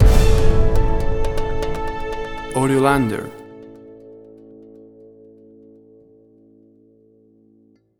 Suspense, Drama, Quirky, Emotional.